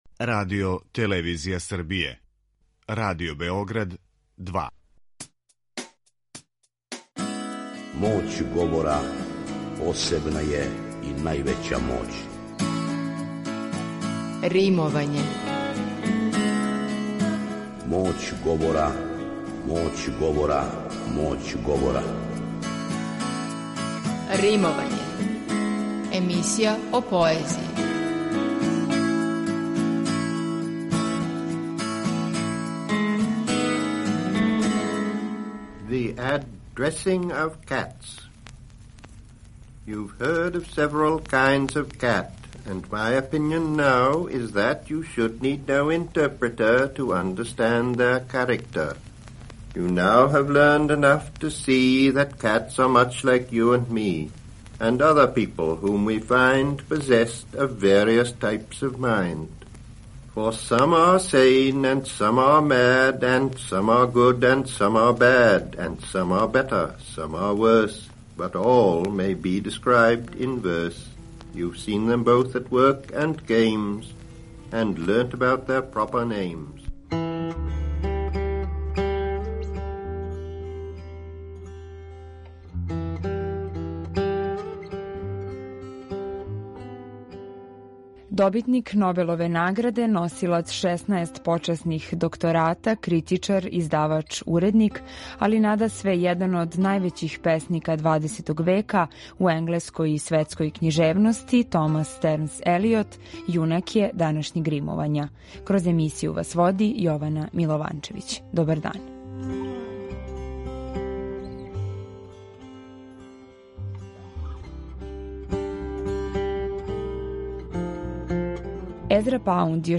Имаћемо прилике да чујемо неколико његових познатих поема, а одломак из најпознатије, која носи назив „Пуста земља", говориће сам Т. С. Елиот.